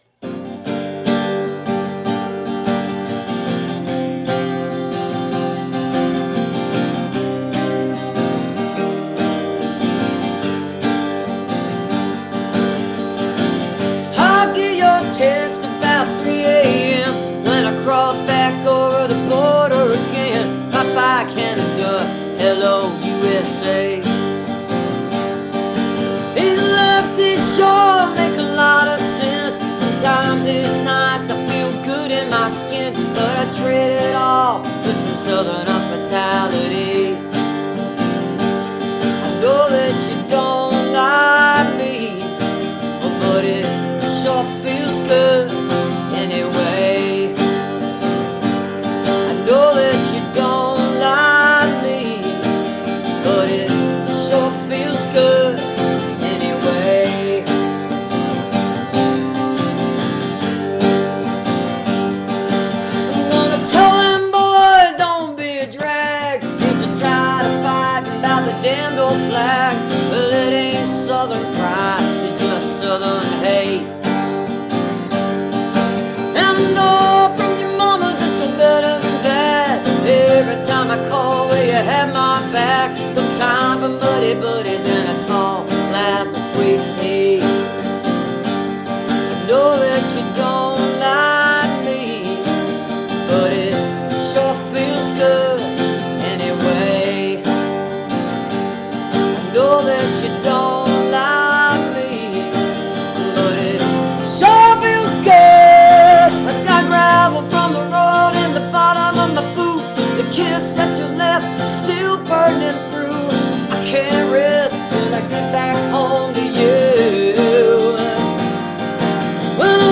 (captured from the live video stream)